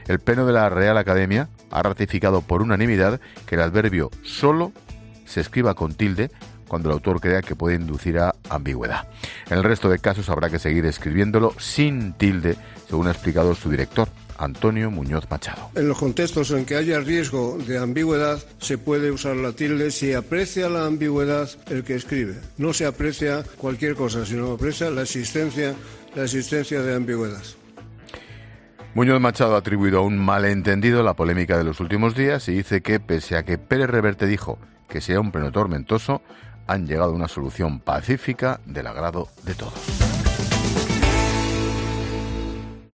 Ángel Expósito te cuenta en La Linterna que la RAE ha cerrado el debate en torno a la tilde en 'solo'